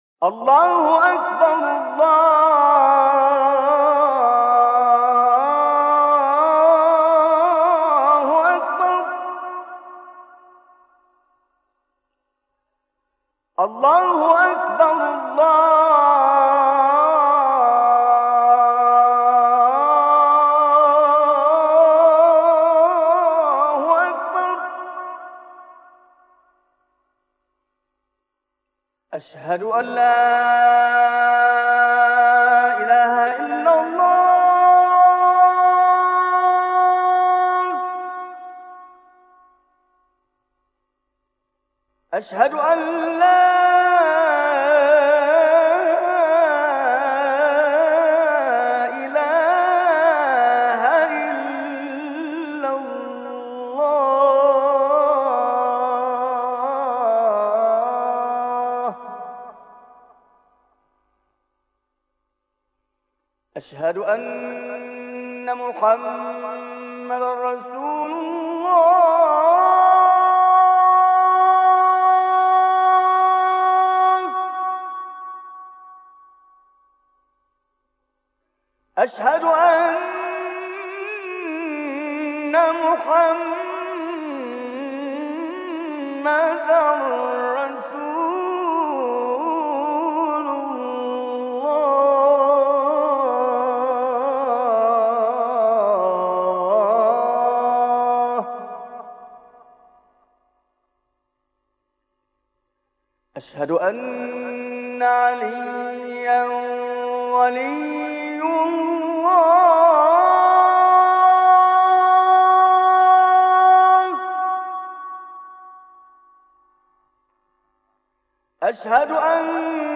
گلبانگ اذان با نوای قاریان مختلف